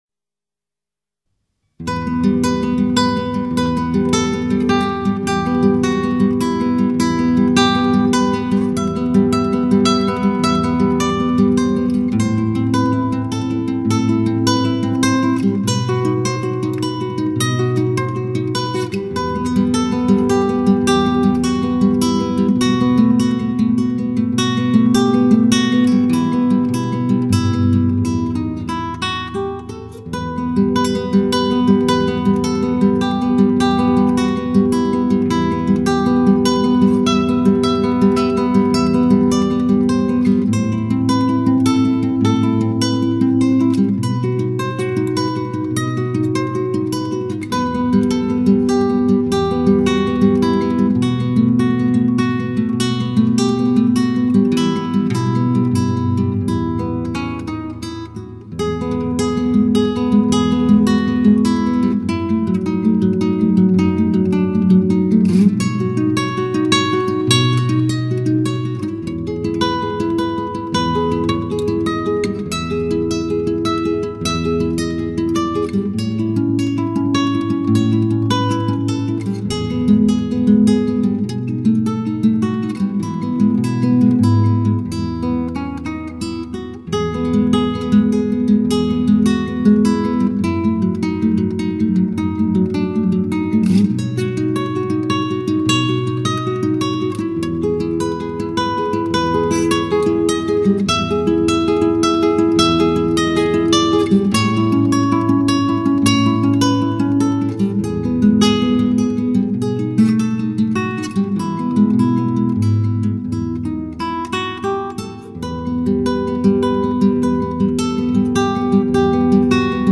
吉他独奏